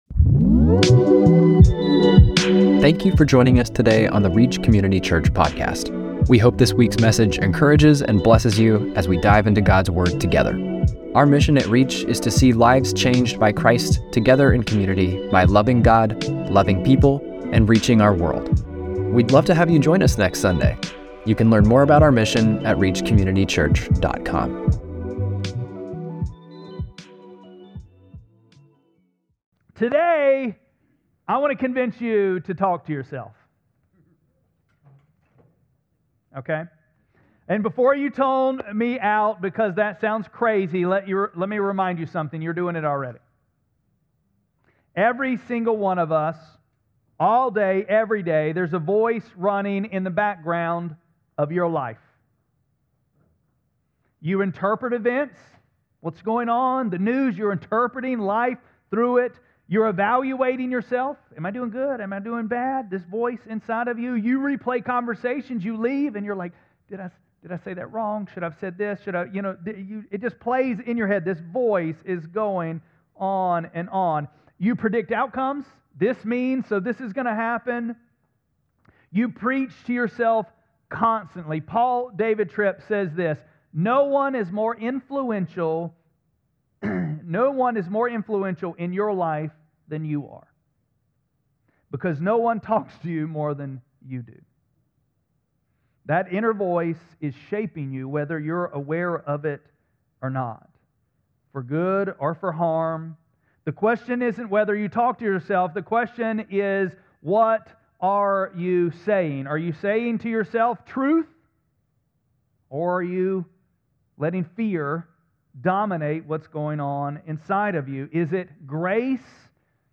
3-1-26-Sermon.mp3